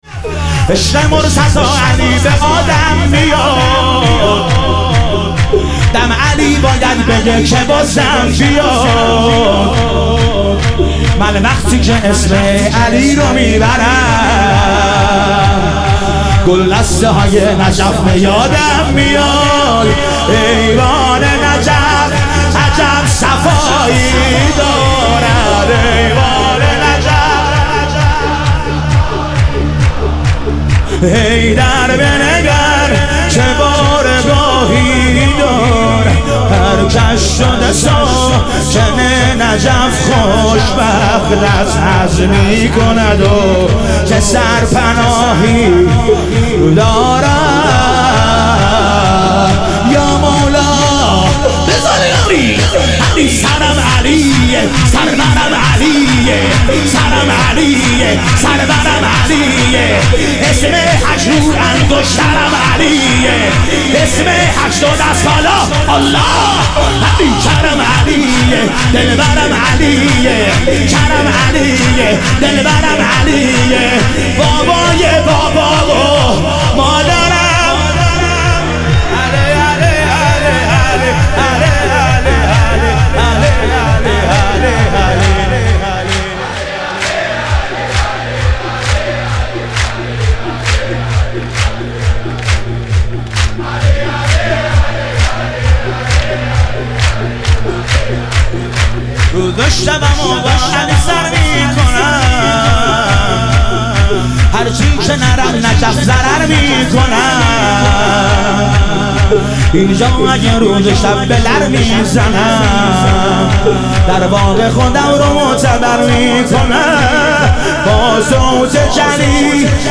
ظهور وجود مقدس امام رضا علیه السلام - شور